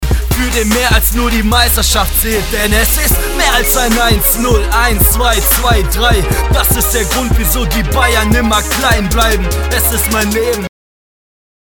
Erstes eigenes Studio - Motu 828 mk3 Hybrid HILFE!
Mein Equipment: Motu 828 mk3 Hybrid - Interface Neumann TLM 102 - Mikrofon 2 mal KRK Rokit 8 - Monitorboxen Big Knob Command System Mein Anliegen ist folgendes: Ich habe schon ein paar Aufnahmen gemacht und auch professionell abmischen lassen, leider ist der Sound einfach dumpf und klingt nicht warm genug, ich hoffe ich schreibe da in einer Sprache die man hier versteht.
Ich füge mal eine Datei hinzu um euch zu zeigen wie eine Aufnahme ( abgemischt) klingt.